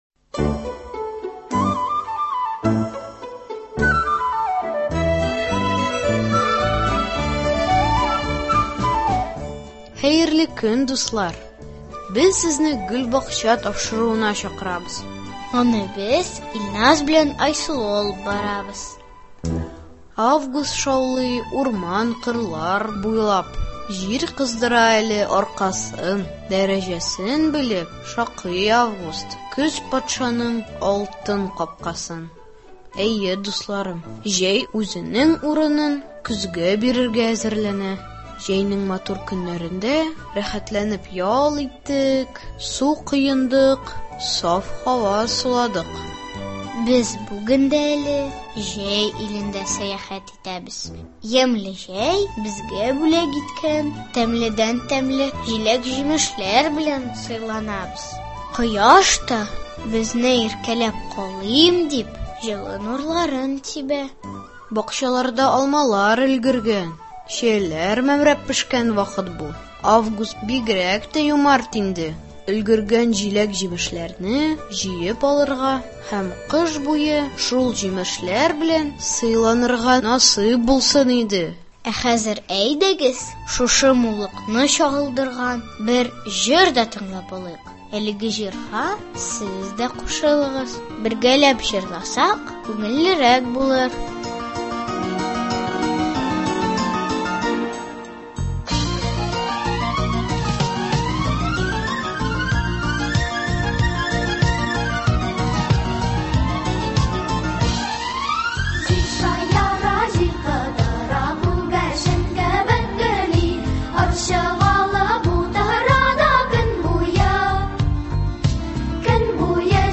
Тапшыруда бакчага йөрүче балалар шигырьләр, әкиятләр сөйли, җырлар башкара, табигатьтәге үзгәрешләрне күзәтеп сәяхәт итә.